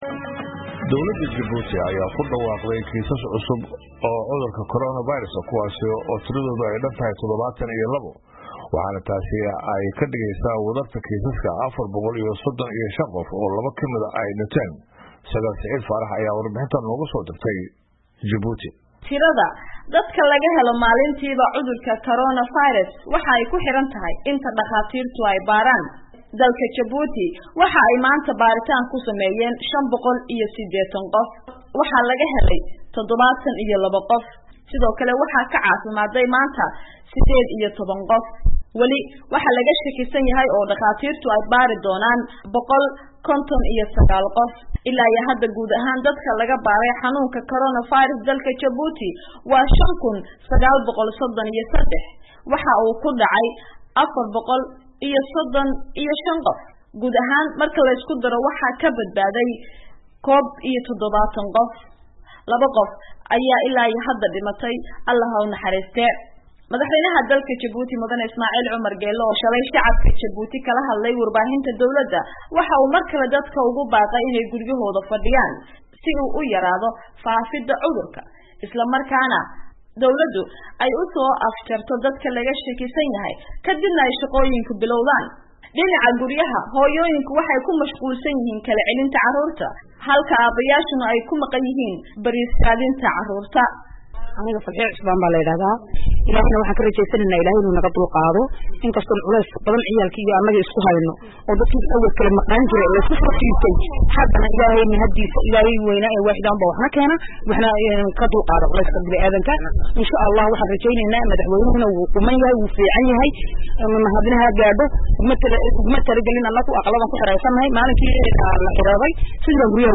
warbixintan nooga soo dirtay Jabuuti.